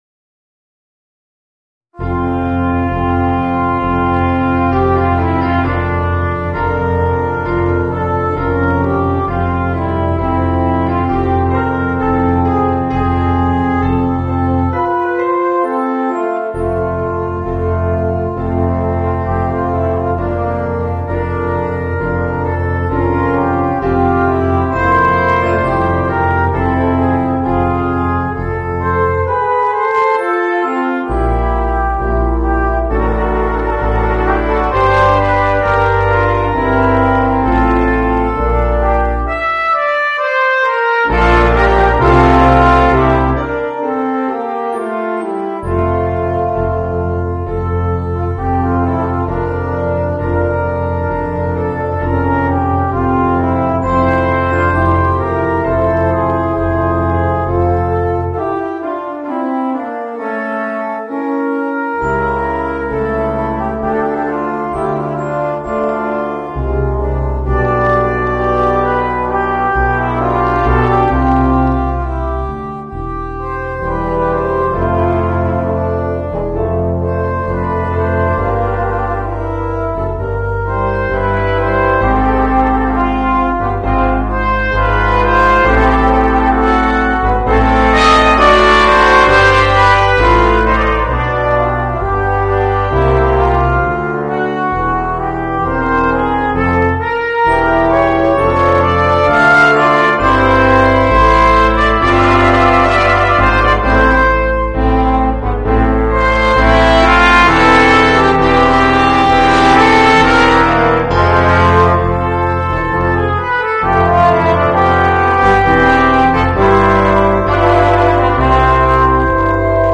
Voicing: 4 - Part Ensemble